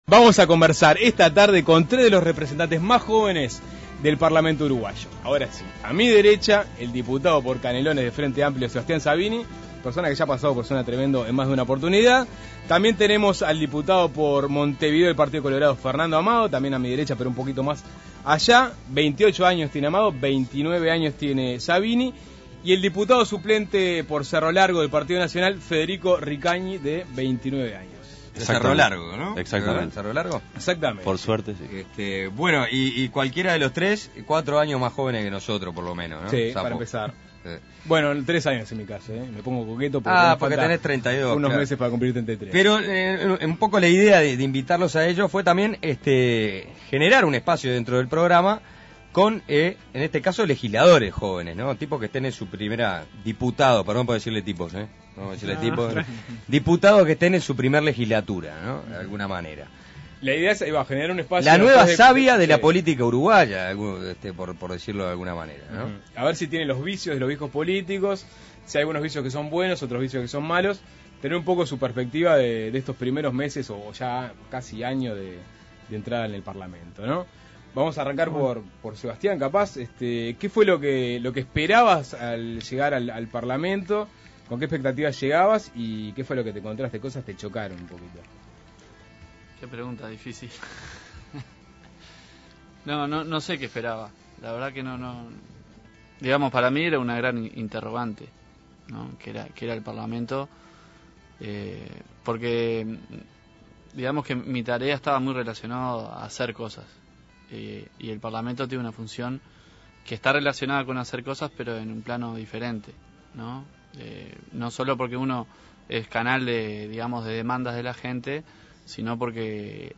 Suenta Tremendo dialogó con tres de los representantes más jóvenes del parlamento: el diputado por Canelones del FA, Sebastián Sabini, el diputado por Montevideo del Partido Colorado, Fernando Amado y el diputado suplente por Cerro Largo del Partido Nacional, Federico Ricagni. La idea fue generar un espacio de intercambio a través del cual puedan escucharse con mayor frecuencia las voces más jóvenes del Palacio Legislativo.